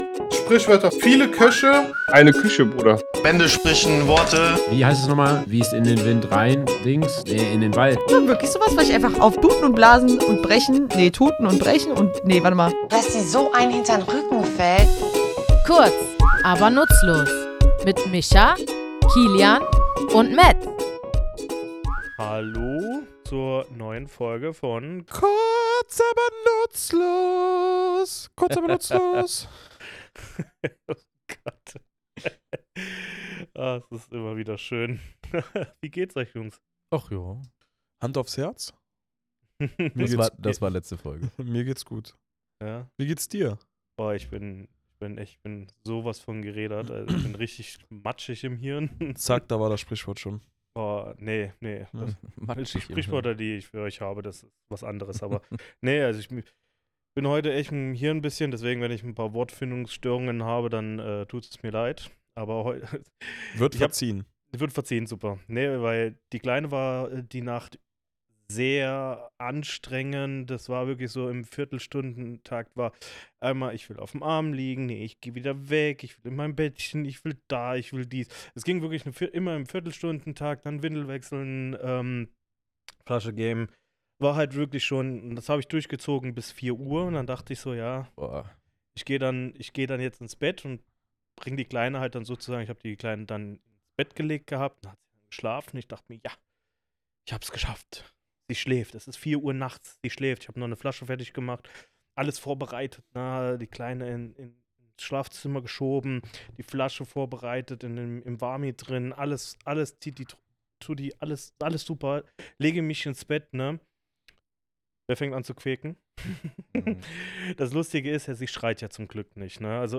Warum sprechen wir von einer „Nummer“, wenn wir vorsichtig sind, und woher stammt dieser Ausdruck eigentlich? Wir, drei tätowierende Sprachliebhaber, nehmen euch in unserem Tattoostudio mit auf eine sprachliche Spurensuche.